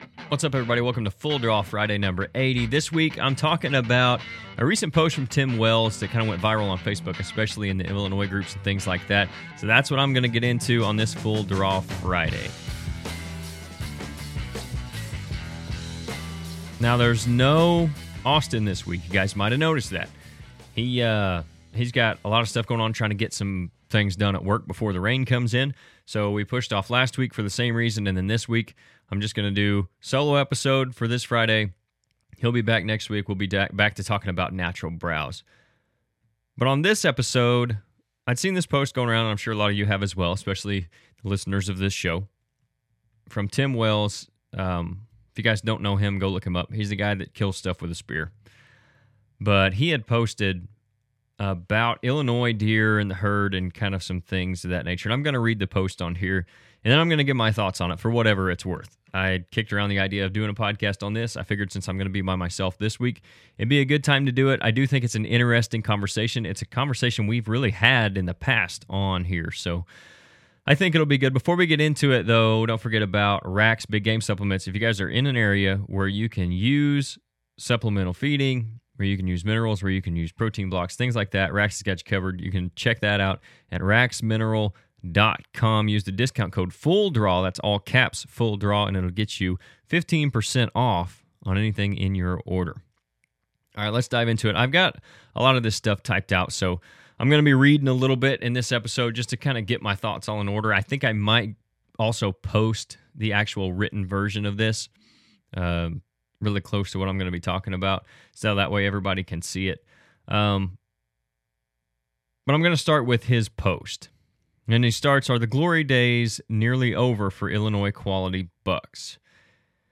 This week the guys are recording from the Illinois Deer Classic.